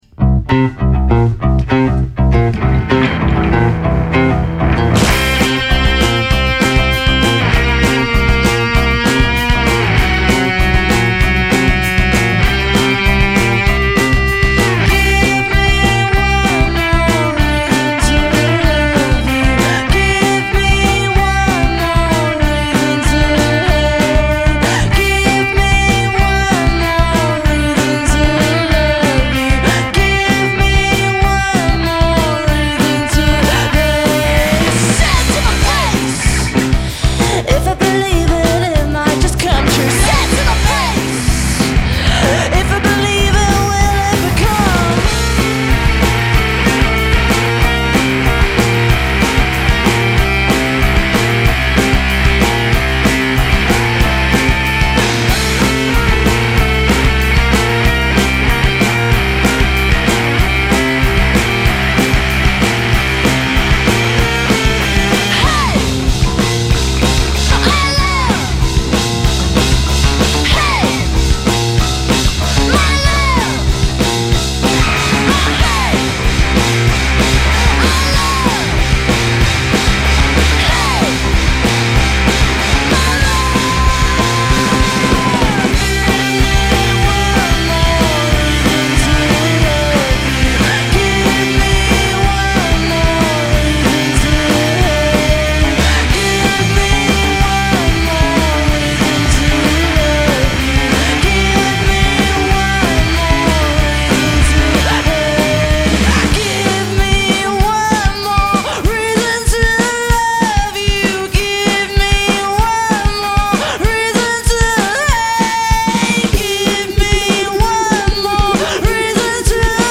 vocalist and guitarist
bassist
drummer